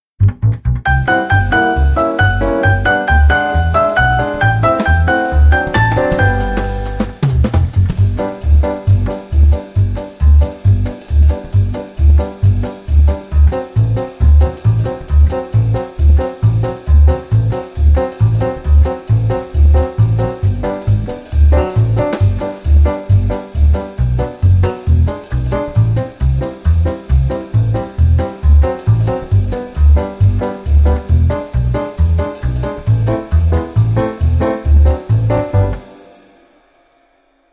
na_okne_sedela_kocka_in_G.mp3